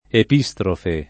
[ ep &S trofe ]